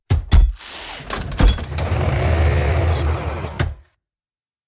exitship.ogg